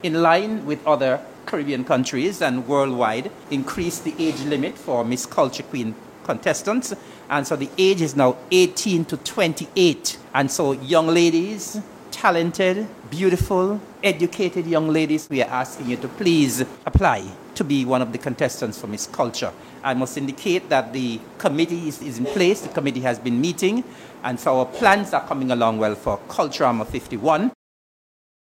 Deputy Premier and Minister of Culture-Nevis, the Hon. Eric Evelyn. This he stated during the Mar. 6th Sitting of the Nevis Island Assembly.